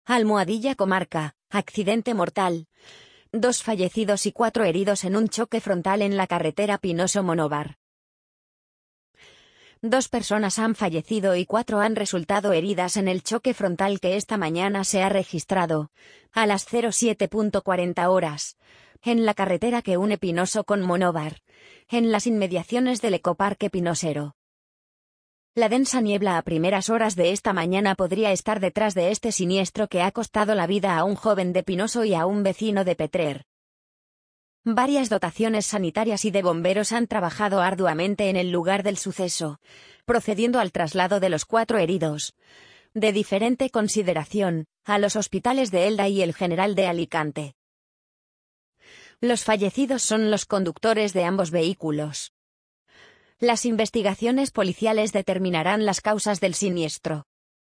amazon_polly_51881.mp3